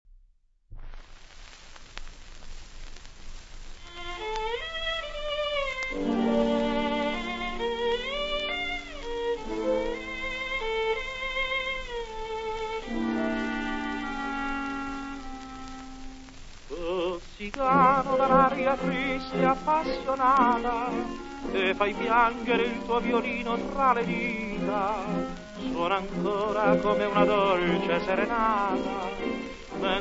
tenore
• musica leggera
• Light music